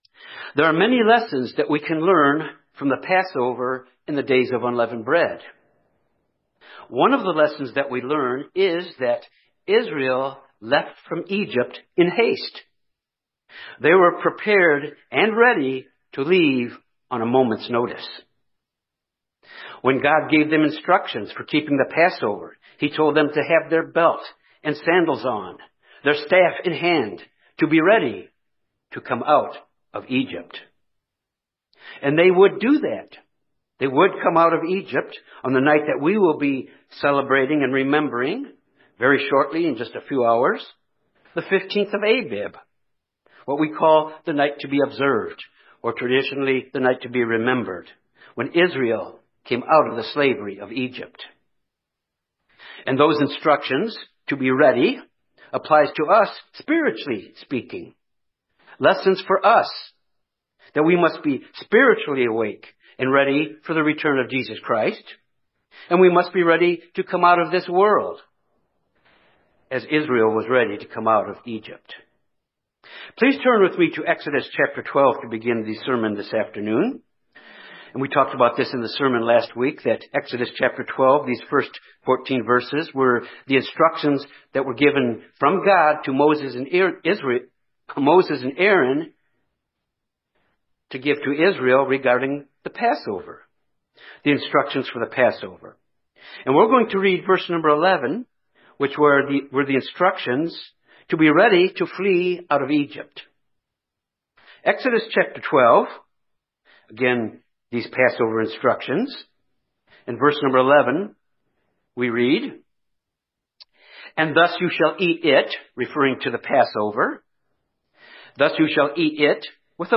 This sermon examine Israel leaving Egypt in triumph, which has spiritual lessons for us.